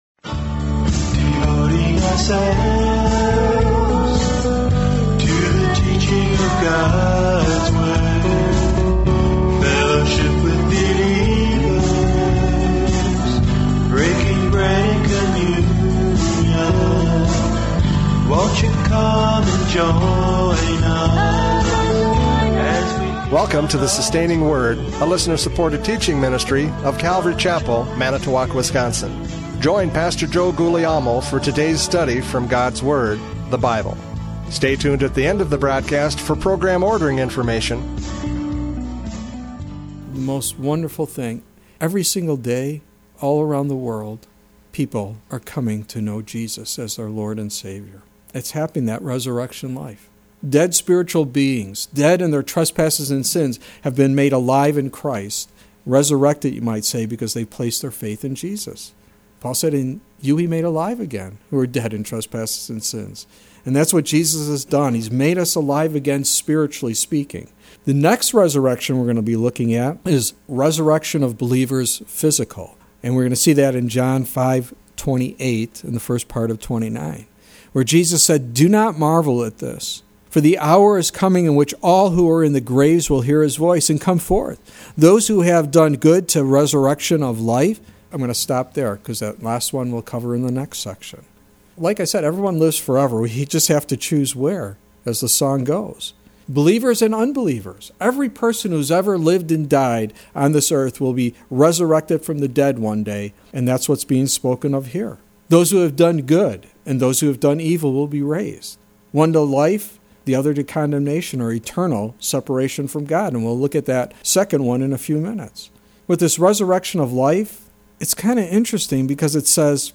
John 5:22-30 Service Type: Radio Programs « John 5:22-30 Equality to Judge!